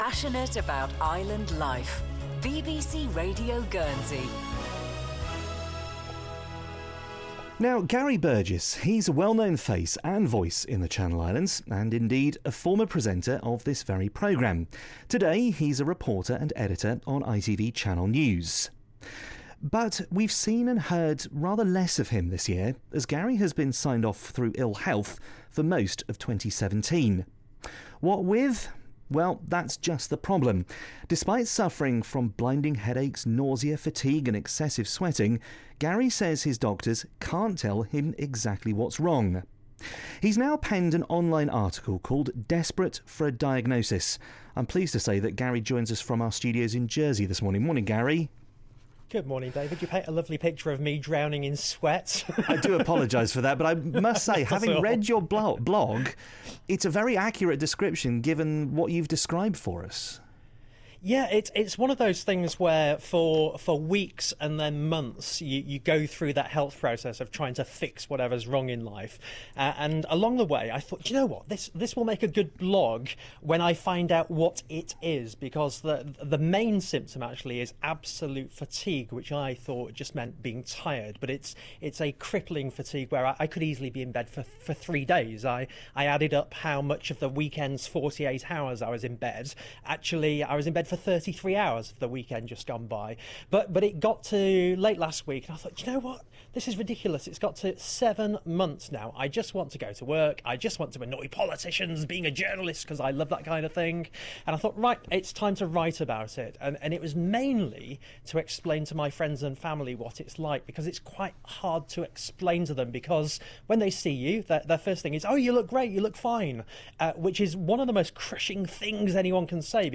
interviewed live on BBC Radio Guernsey